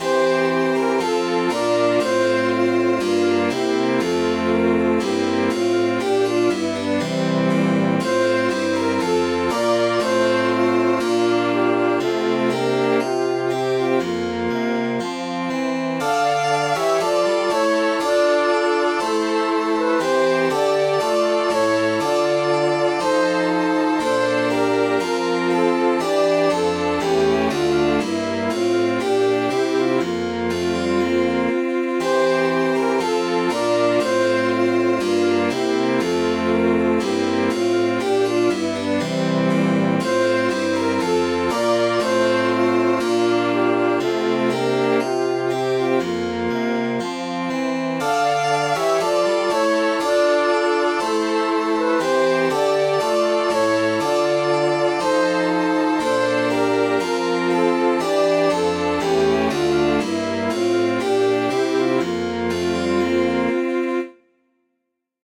Midi File, Lyrics and Information to Love Me Little, Love Me Long